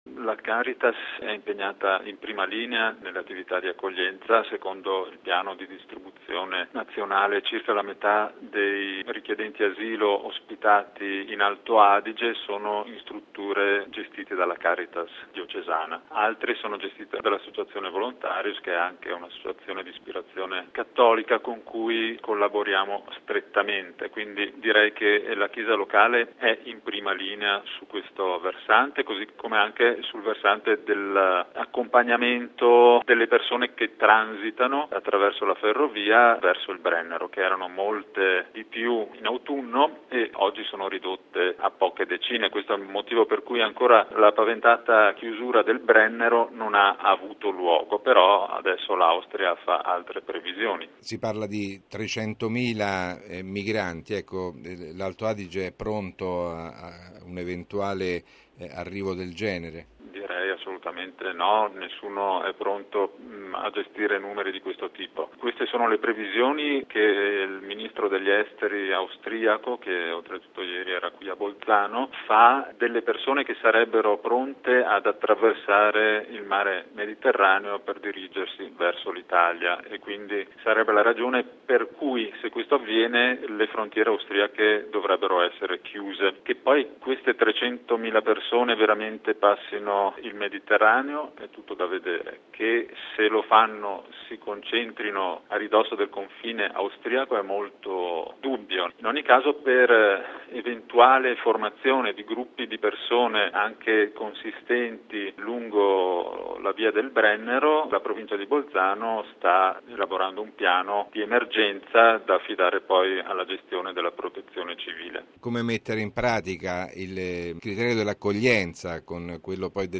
Al centro del vertice di ieri a Roma tra Italia e Austria la questione migranti e l'ipotesi, per ora ritirata, di una possibile chiusura della frontiera del Brennero da parte di Vienna, che teme che dall’Italia arrivino 300 mila persone. Sulla situazione nell’area